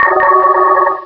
pokeemerald / sound / direct_sound_samples / cries / gorebyss.aif
-Replaced the Gen. 1 to 3 cries with BW2 rips.